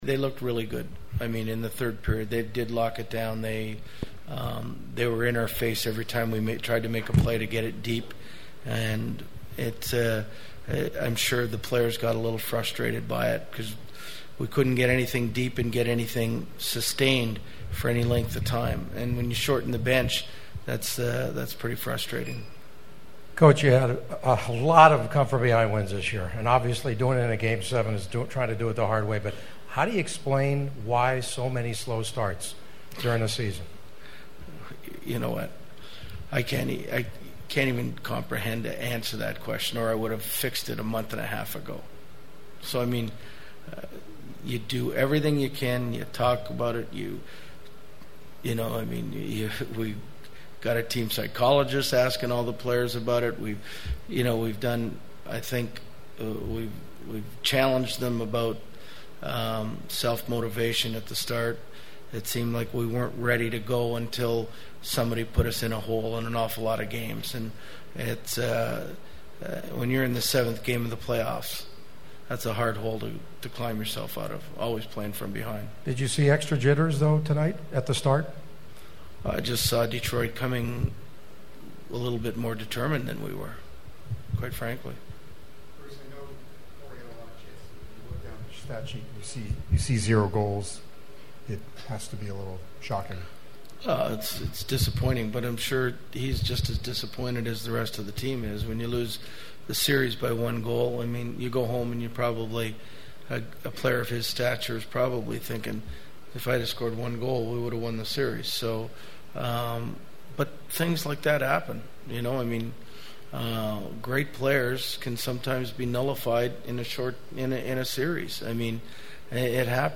The Ducks had an NHL best 15 come from behind wins during the regular season but their magic also ran out something that was one of my themes of questioning in the postgame locker room.
Ducks coach Bruce Boudreau: